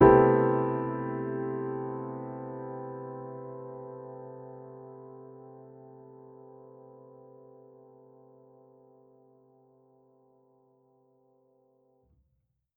Index of /musicradar/jazz-keys-samples/Chord Hits/Acoustic Piano 1
JK_AcPiano1_Chord-Cmaj13.wav